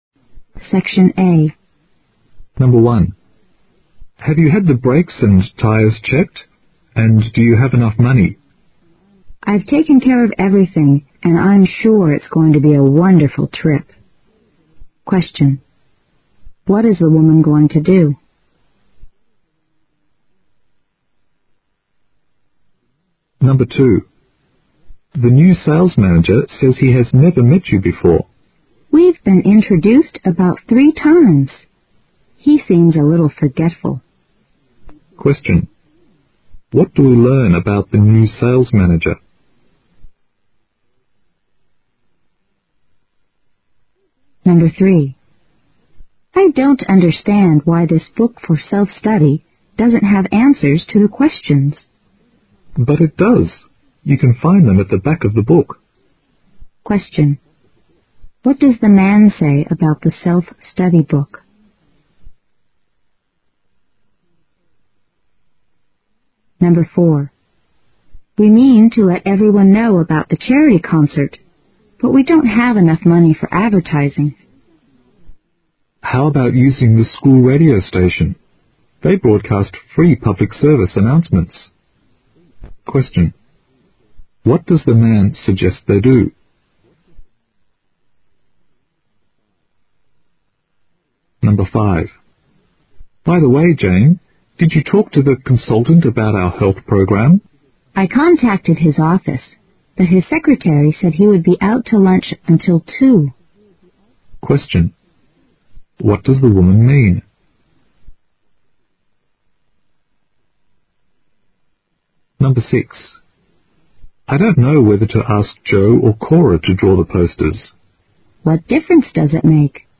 Part I Listening Comprehension (20 minutes)